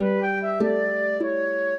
flute-harp
minuet14-3.wav